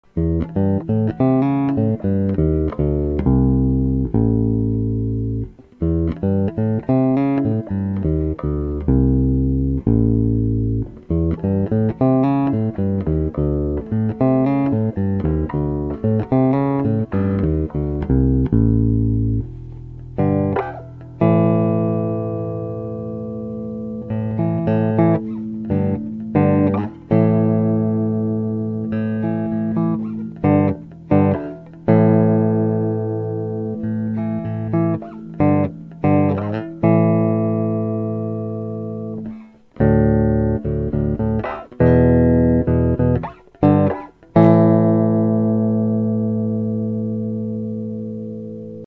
Vintage Jazz Bass sounds with a softer touch. Using Alnico II magnets makes this set slightly fatter and warmer and gives them a more dynamic response to picking.
Bridge